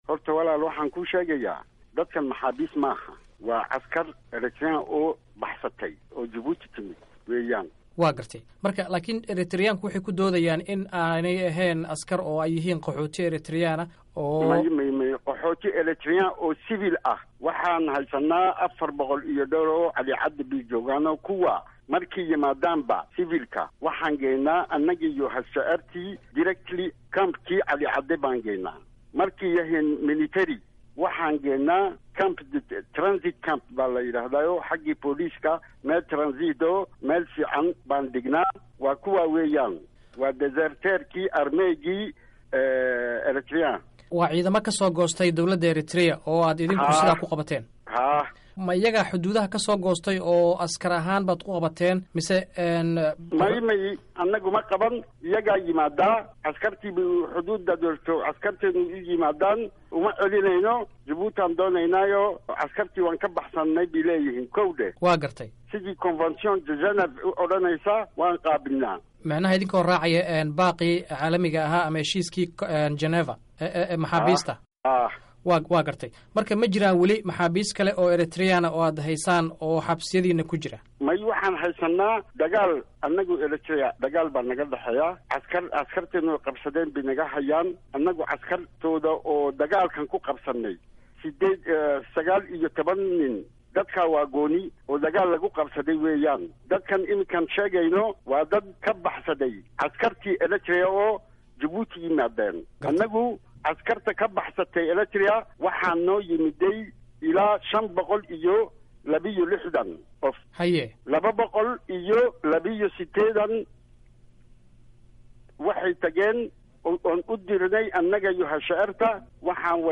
Waraysiga wasiirka arrimaha gudaha Djibouti